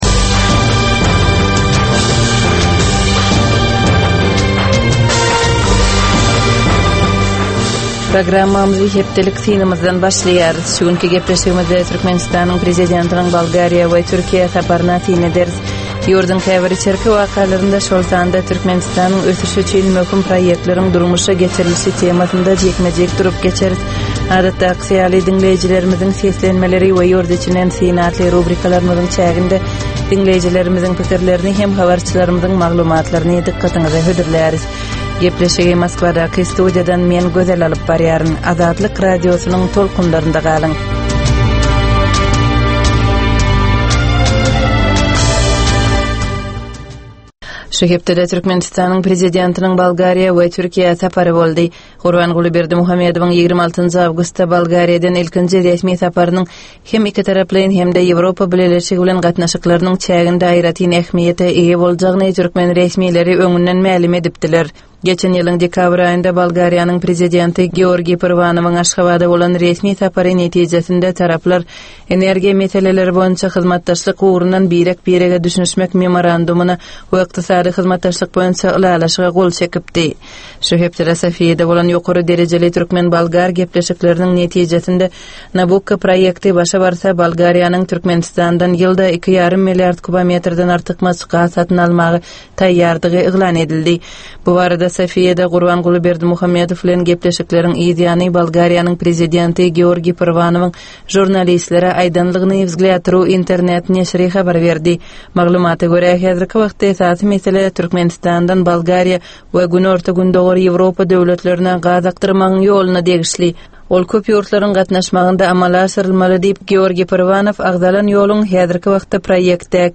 Tutus geçen bir hepdänin dowamynda Türkmenistanda we halkara arenasynda bolup geçen möhüm wakalara syn. 25 minutlyk bu ýörite programmanyn dowamynda hepdänin möhüm wakalary barada gysga synlar, analizler, makalalar, reportažlar, söhbetdeslikler we kommentariýalar berilýar.